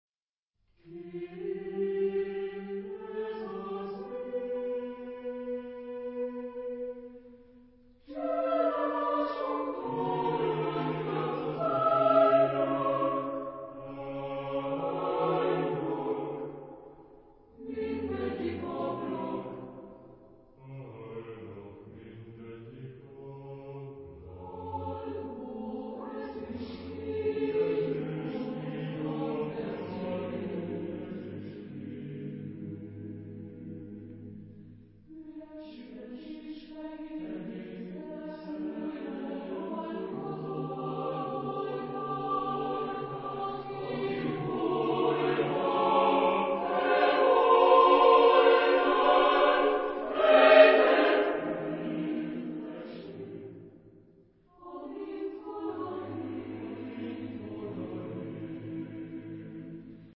Genre-Style-Forme : Chœur ; Profane ; contemporain
Type de choeur : SATB  (4 voix mixtes )
Tonalité : tonal avec variations modales